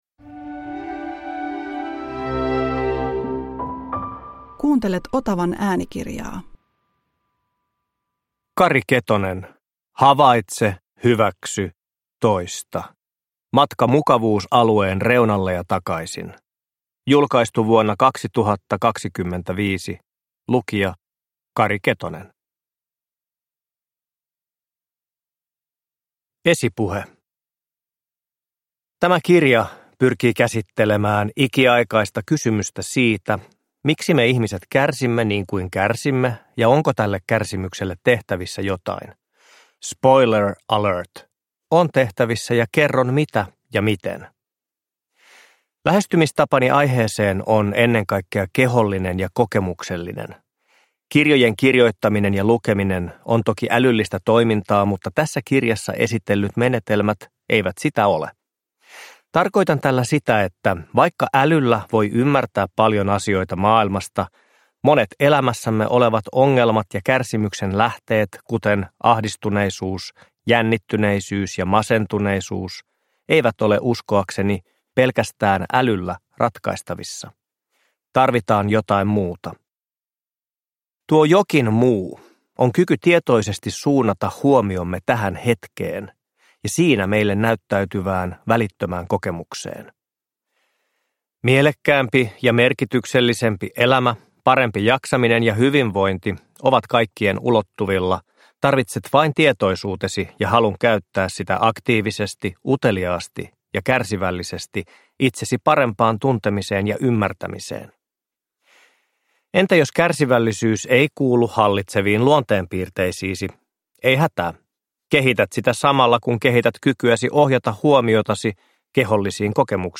Havaitse. Hyväksy. Toista. – Ljudbok
Uppläsare: Kari Ketonen